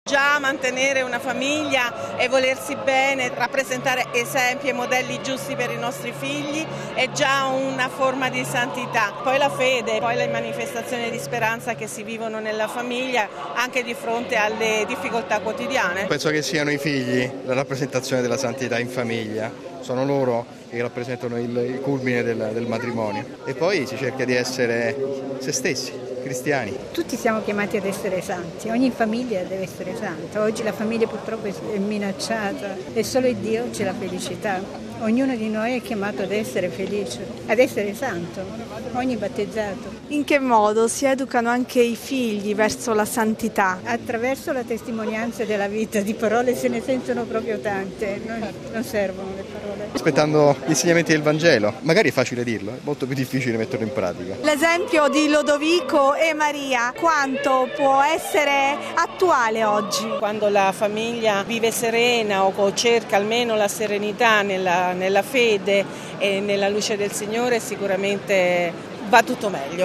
Famiglie in Piazza San Pietro per la canonizzazione dei genitori di S. Teresina